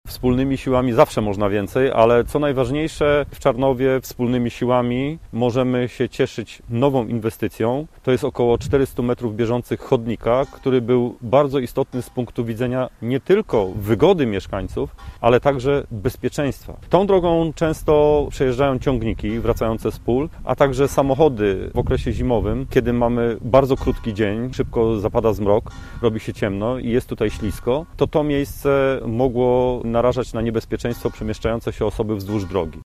– Wspólne wykorzystanie funduszu sołeckiego i dofinansowania z gminy sprawiło, że bezpieczeństwo mieszkańców Czarnowa wzrosło – mówi Marek Cebula, burmistrz Krosna Odrzańskiego: